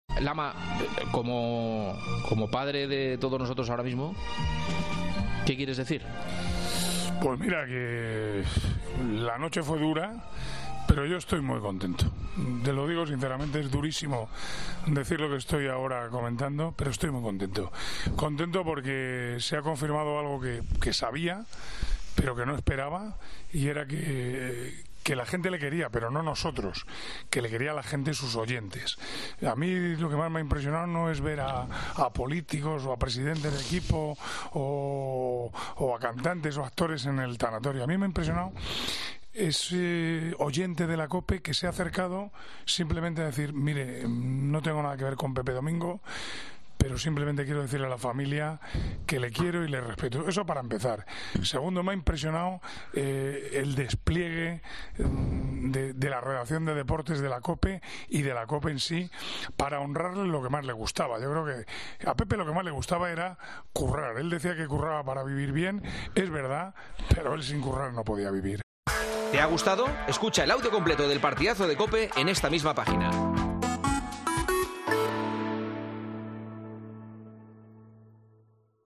Manolo Lama expresaba con Juanma Castaño lo que sintió durante todo el día.